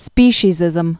(spēshē-zĭzəm, -sē-)